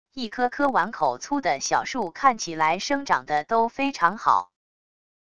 一颗颗碗口粗的小树看起来生长的都非常好wav音频生成系统WAV Audio Player